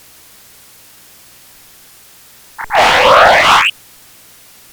Si, mediante el software mencionado anteriormente, hacemos un zoom a la imagen se obtendrá un sonido a 16KWMA correspondiente a una imagen donde el cuerpo del planeta cubre casi todo el sonido y todas las partes del anillo, salvo la que queda sobre la superficie del planeta, han desaparecido. Puesto que la imagen aparece borrosa y muy oscura se escuchará que el círculo no presenta bordes. El sonido será bajo a la izquierda e irá decayendo hacia la derecha.